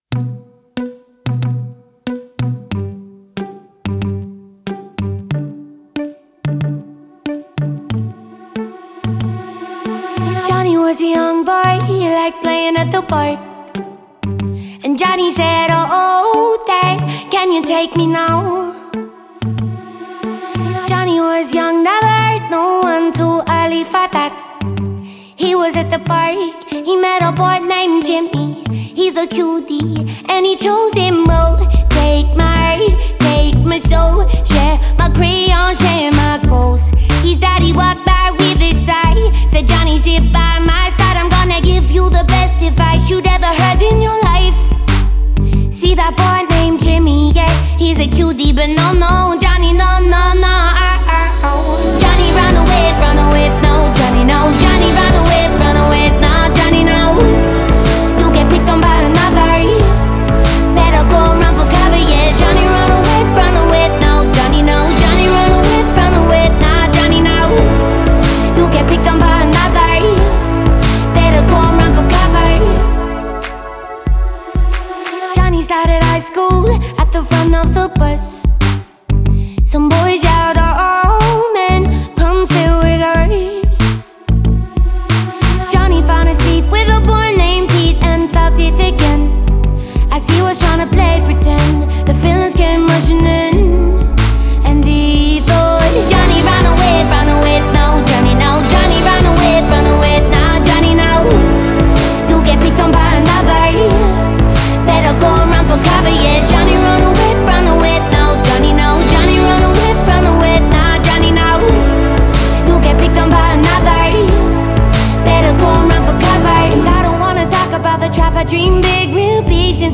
Альтернатива